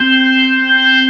55o-org14-C4.aif